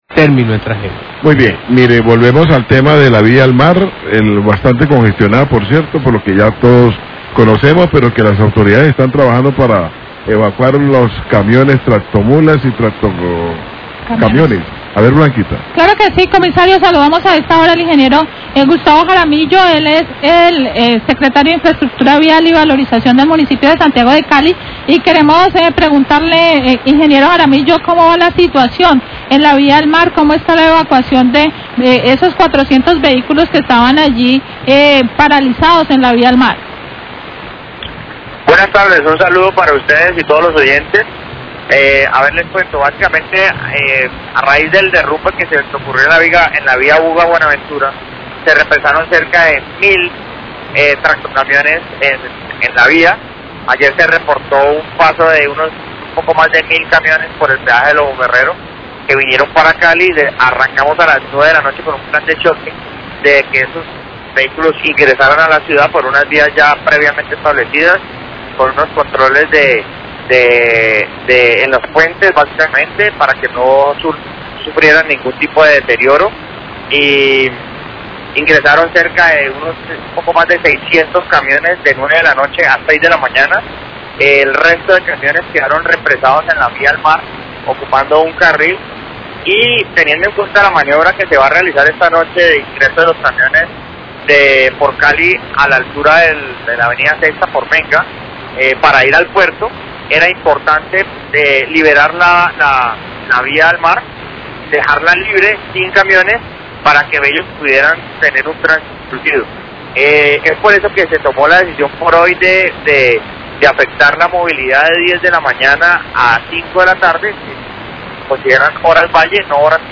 Radio
El secretario de infraestructura, Gustavo Jaramillo, habló sobre la evacuación de 400 vehículos de carga que estaban en la vía al Mar, que durante la noche ingresaron a la ciudad.